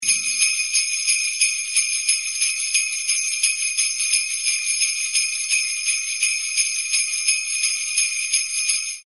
SleighBells1.mp3